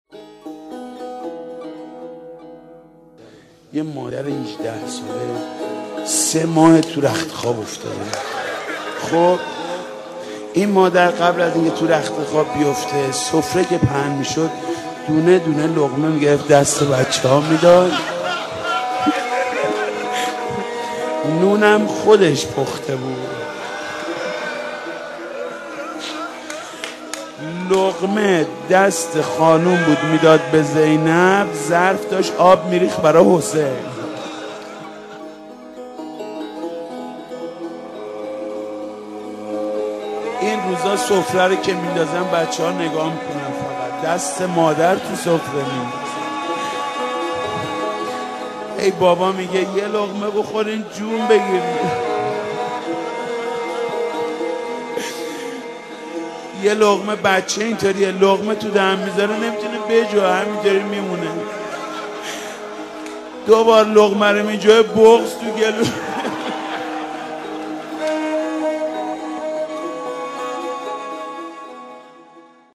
نوحه خوانی